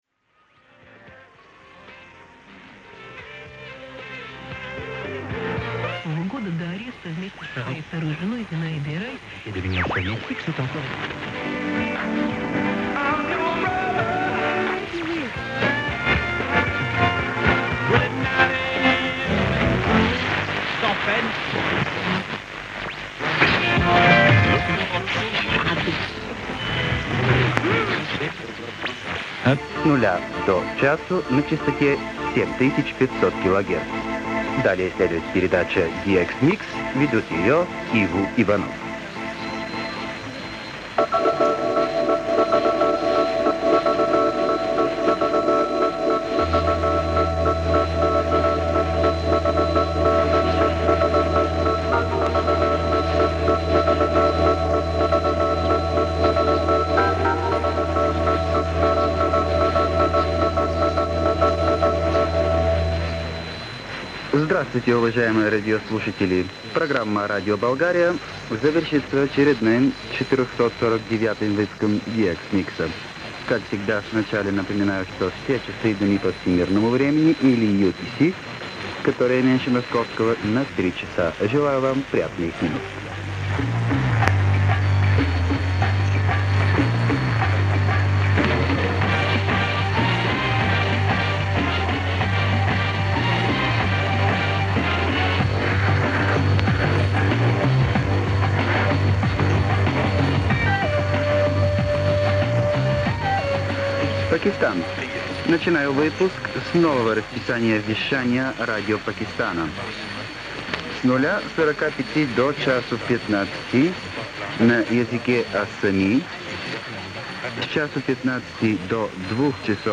ФАНАТАМ РЕТРОРАДИОПРИЕМА.Передача Болгарского радио для DX-систов(любителей приема дальних и редких станций)